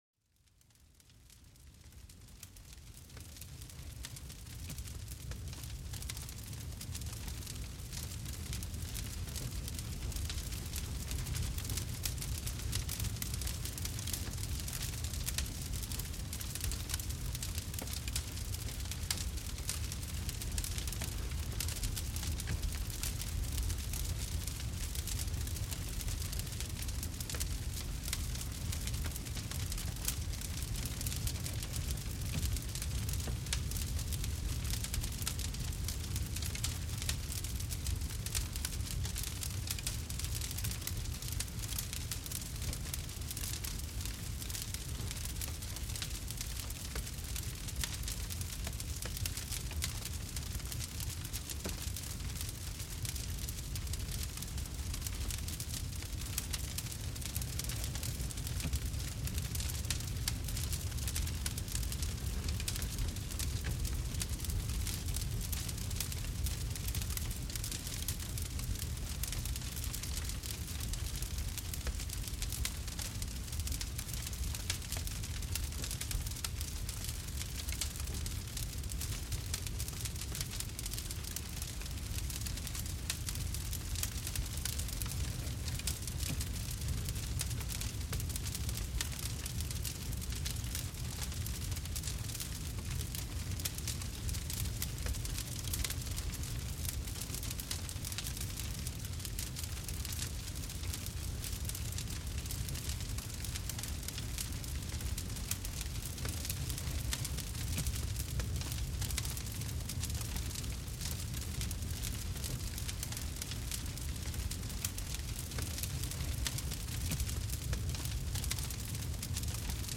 Crépitements d'un Feu Apaisant : Laissez-vous Bercer par sa Chaleur
Plongez dans l'ambiance réconfortante d'un feu crépitant, un véritable cocon de chaleur et de tranquillité. Les sons doux et répétitifs des flammes apaisent l'esprit et relâchent les tensions du corps.